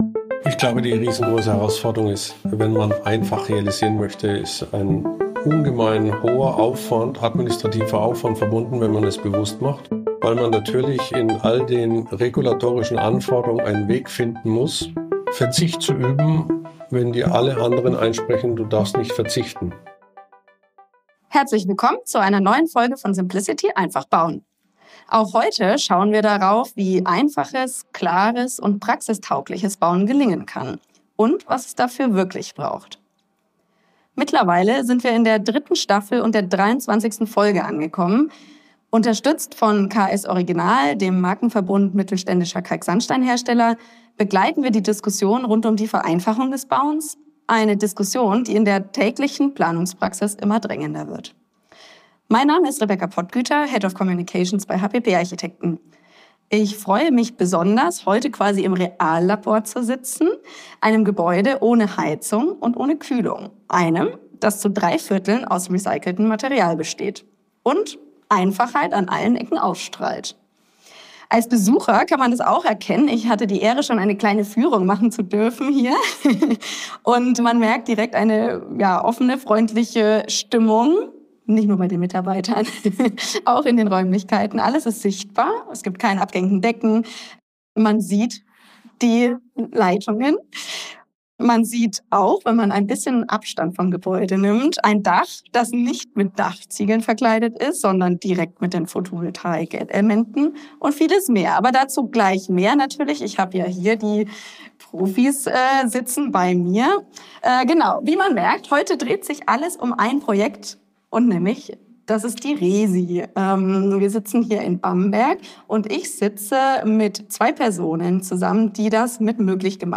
Im Gespräch erläutern sie, was es mit dem Leitgedanken der „Frugalität“ auf sich hat und wie sich dieser durch den gesamten Lebenszyklus ziehen soll.